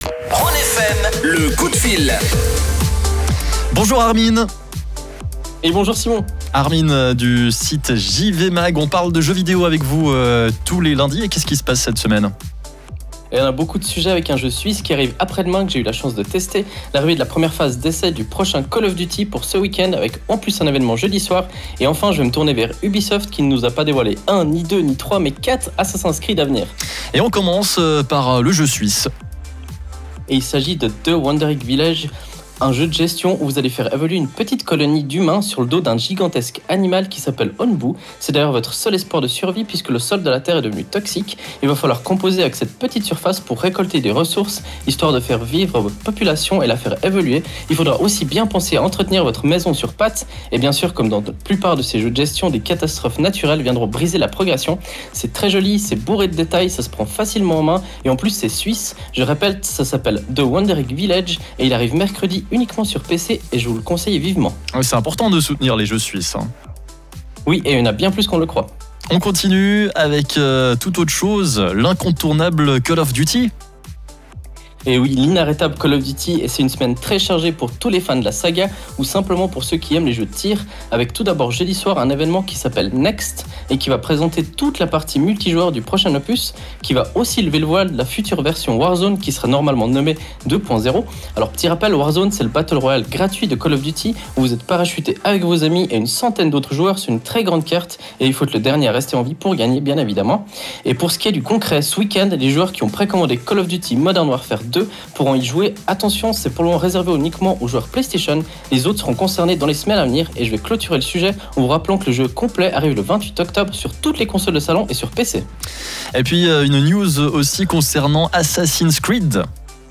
Pour ce qui est du direct, il est à réécouter juste en dessus.